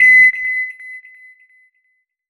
beep_07.wav